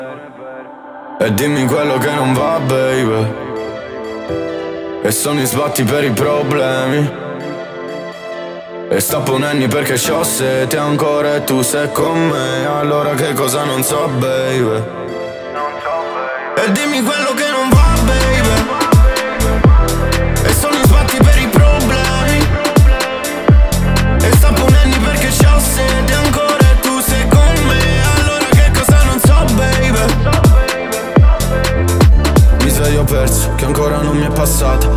Жанр: Рэп и хип-хоп / Иностранный рэп и хип-хоп